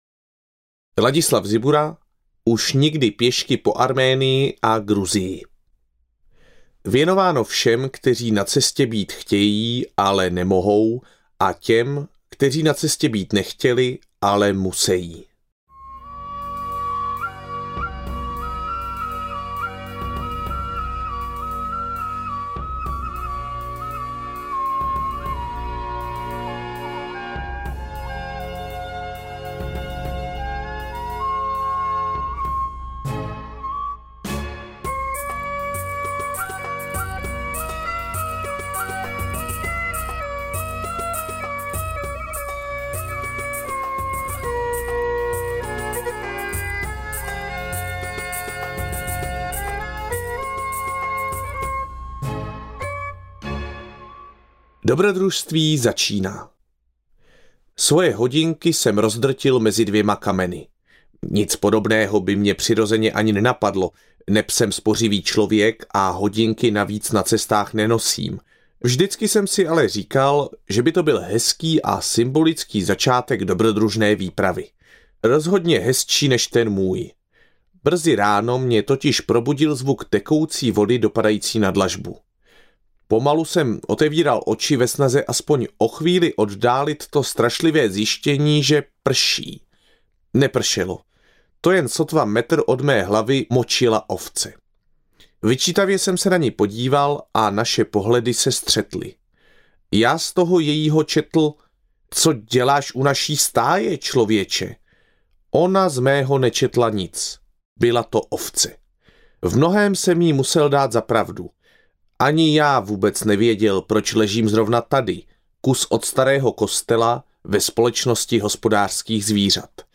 AudioKniha ke stažení, 48 x mp3, délka 9 hod. 52 min., velikost 539,1 MB, česky